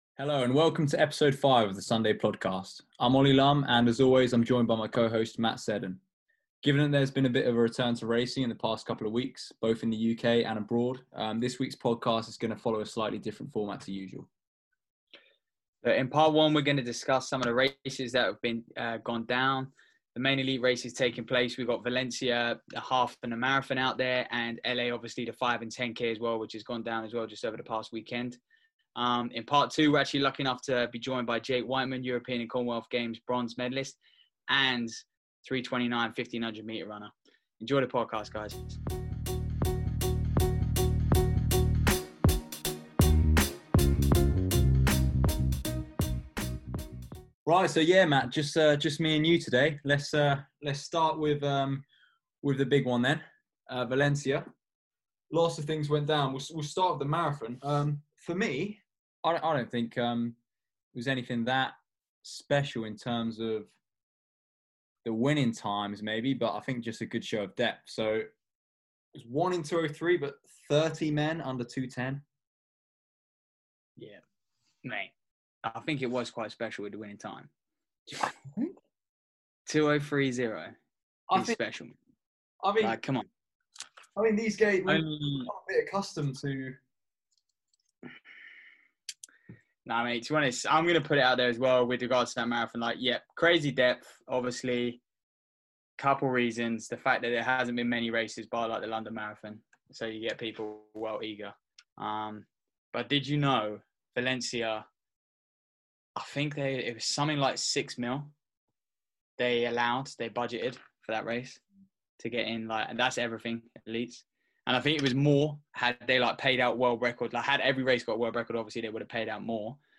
Facebook Twitter Headliner Embed Embed Code See more options In part 1 of this episode, we discussed some recent race results; including the Valencia Half-Marathon and 'The Track Meet' in California. Then, in part 2, we spoke to GB distance star, Jake Wightman. Jake reflected on his 2020 season, including his blistering 3:29 1500m PB in Monaco.